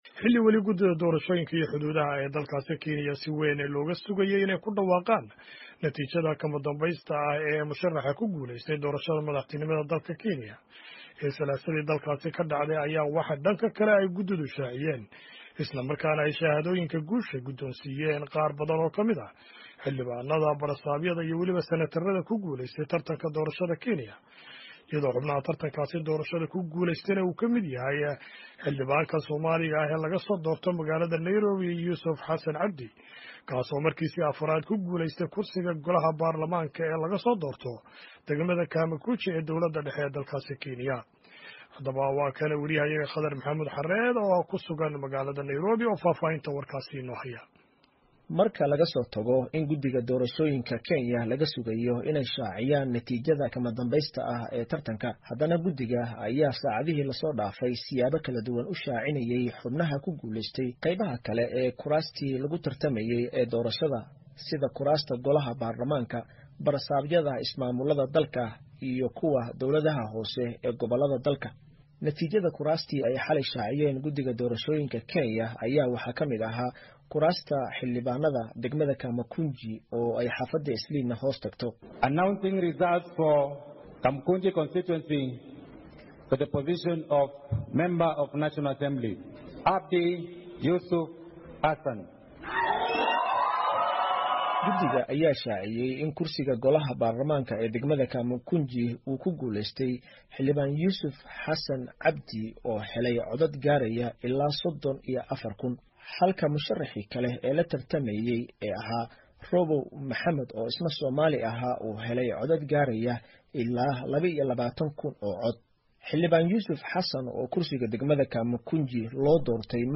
warkan Nairobi kasoo diray.